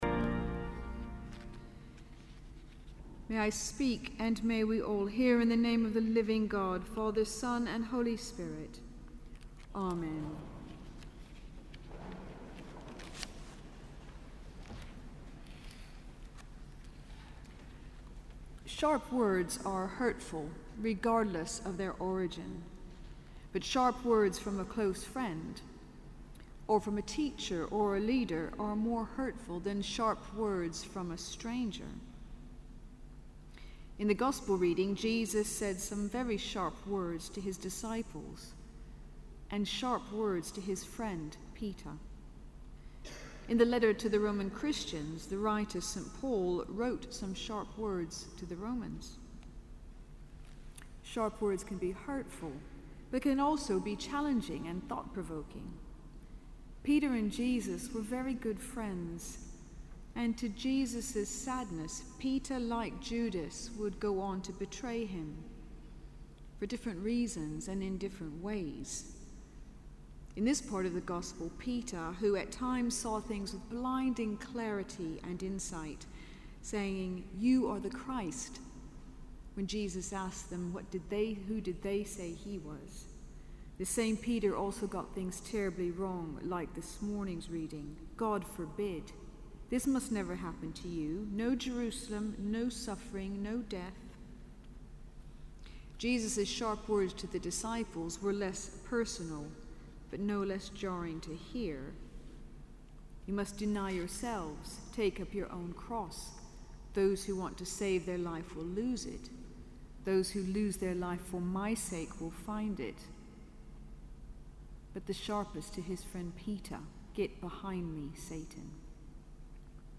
Sermon: Cathedral Eucharist - 31 August 2014